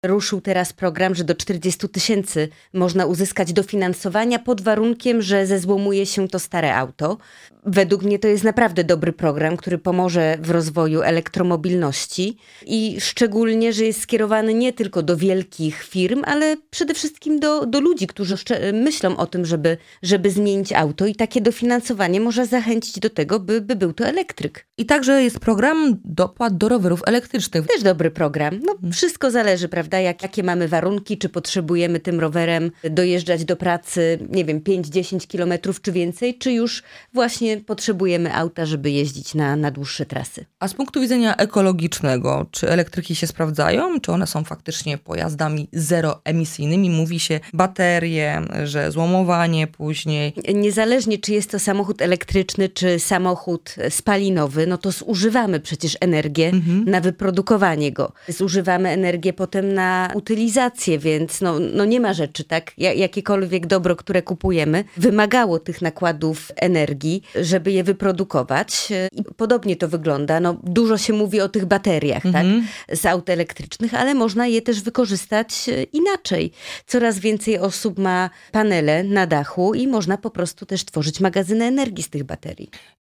Samochody elektryczne, Zielony Ład, projekt obywatelski „Stop łańcuchom”, zakaz polowania we Wrocławiu, interwencja w sprawie używania fajerwerków w Ślężańskim Parku Krajobrazowym oraz referendum ws. odwołania prezydenta Jacka Sutryka – te zagadnienia poruszyliśmy z posłanką na Sejm Małgorzatą Tracz z KO (partia Zieloni).